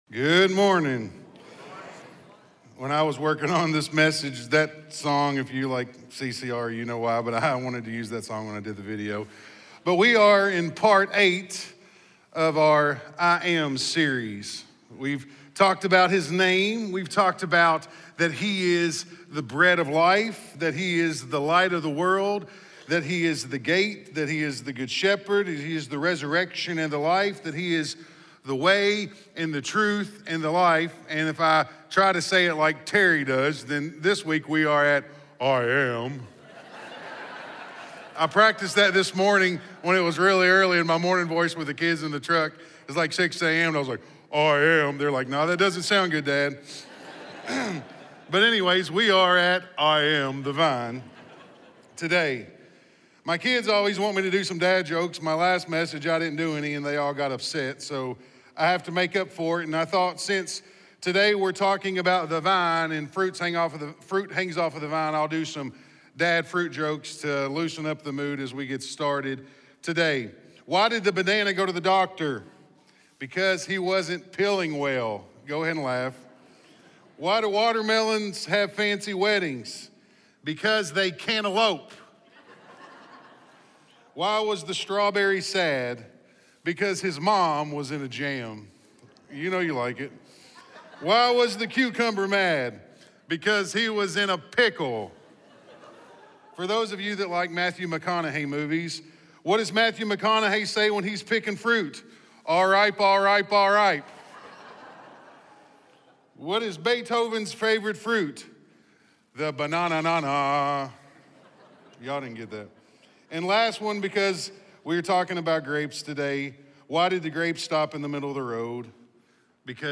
This sermon is Part 8 of the “I AM” series, focusing on Jesus’ declaration, “I am the true vine” from John 15. Building on earlier messages about Jesus as the Bread of Life, the Light of the World, the Gate, the Good Shepherd, the Resurrection and the Life, and the Way, the Truth, and the Life, this message unpacks what it really means to abide in Christ and bear lasting spiritual fruit.